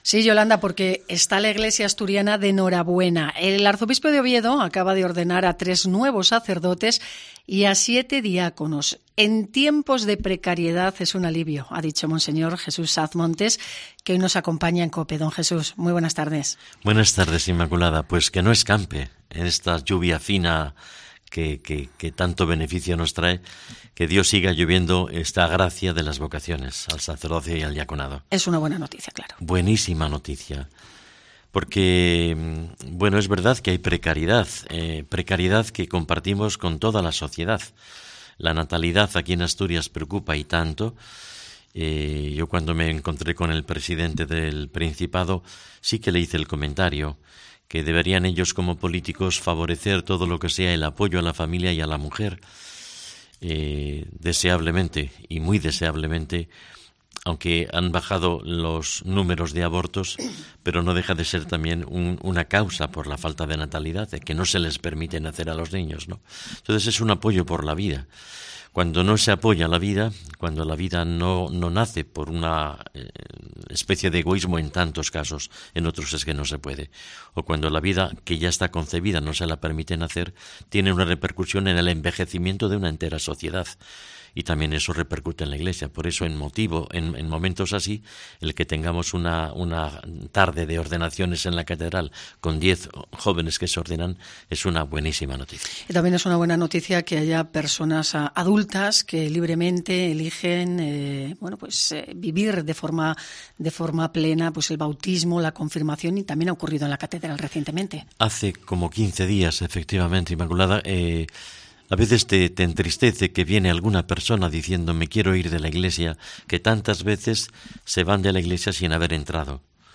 Entrevista al Arzobispo de Oviedo